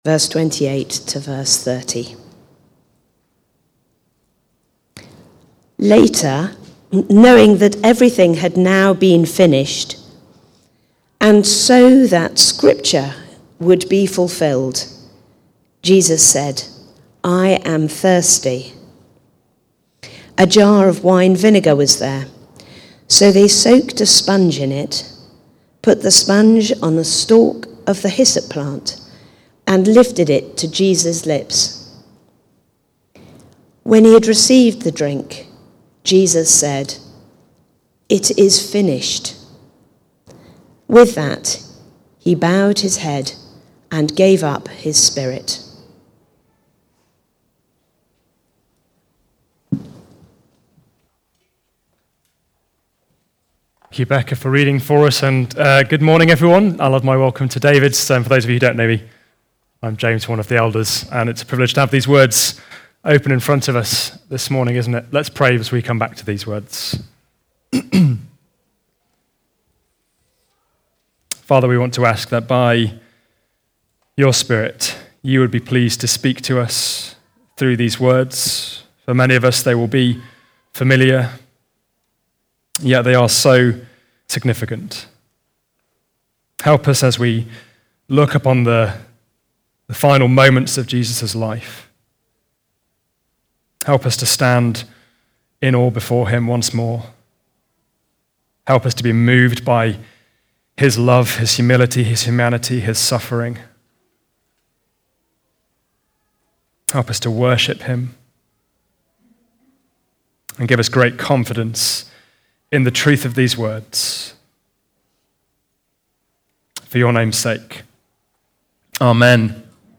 Preaching
The Finish (John 19:28-30) from the series Life From Death. Recorded at Woodstock Road Baptist Church on 22 March 2026.